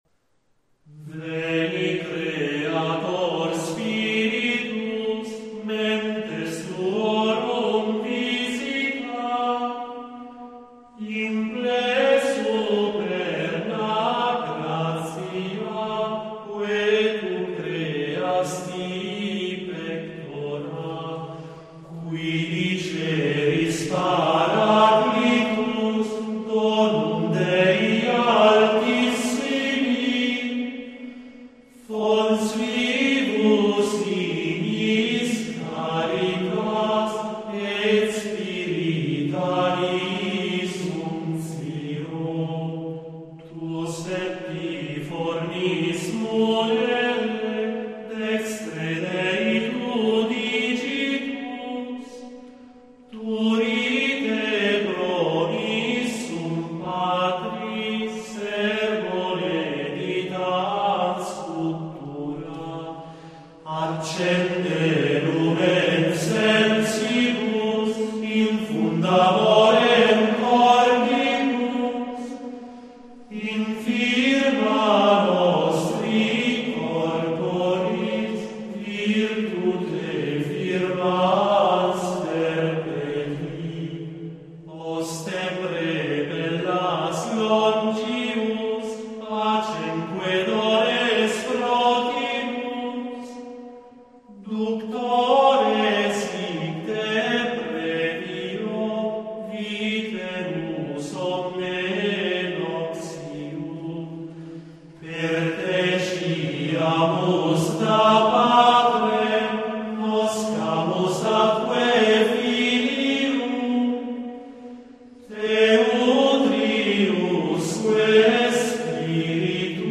Attribuito a volte a Rabano Mauro († 856) a volte a Carlo Magno e altri, prevede sei quartine (in dimetri giambici) cantate sulla stessa melodia.